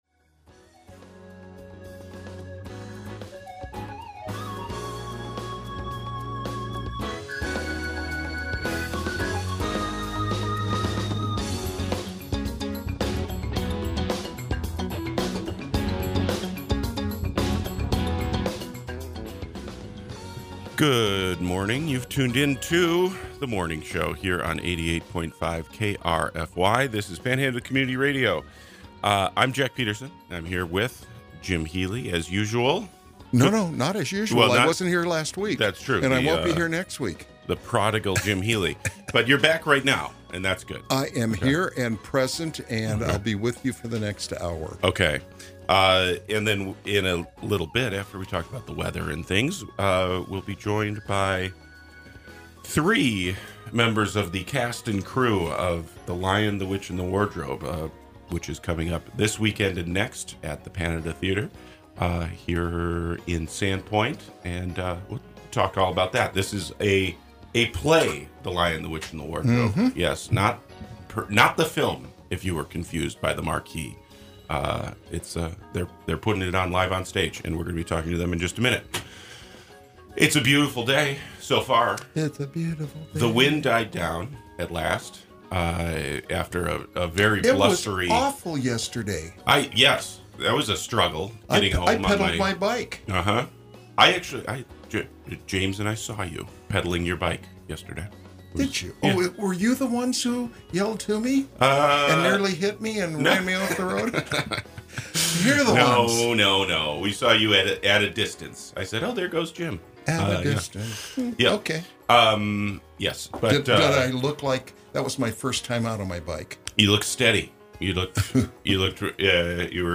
These local shows air at 8 a.m. every Monday-Friday on 88.5 KRFY Community Radio.